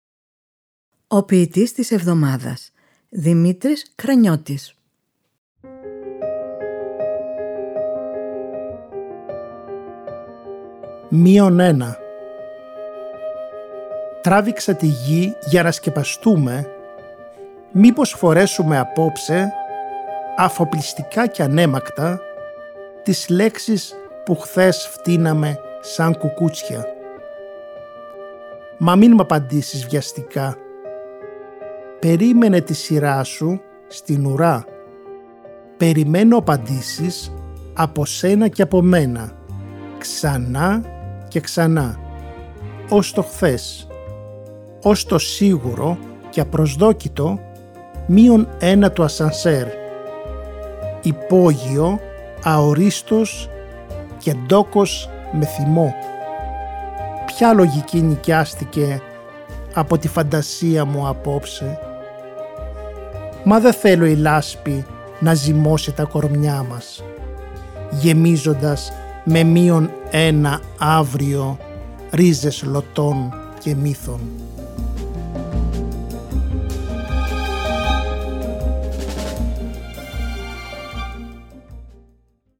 Μουσική επιμέλεια: Μαρία Ρεμπούτσικα